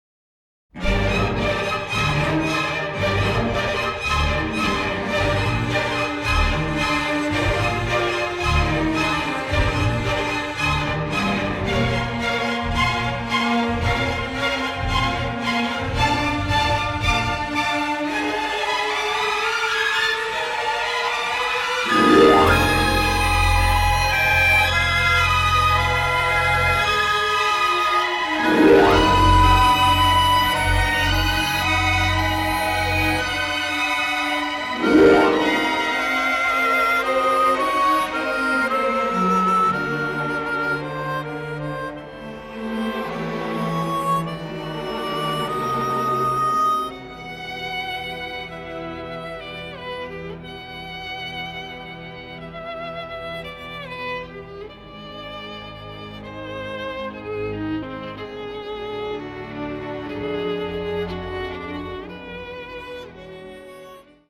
original soundtrack
newly remastered